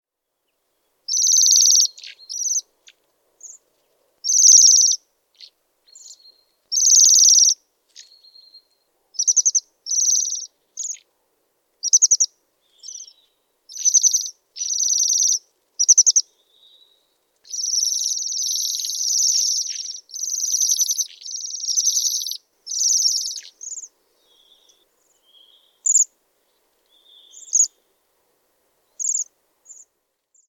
Kun pihlajanmarjoja on paljon, värikkäät iloisesti helisevät tilhiparvet ovat tuttu näky ympäri Suomen.
Bombycilla garrulus
Laulu: Hiljaista heleää sirinää.
Tilhi_-_laulua_ja_kutsua.mp3